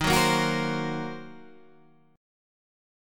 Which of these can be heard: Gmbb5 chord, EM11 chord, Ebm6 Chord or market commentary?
Ebm6 Chord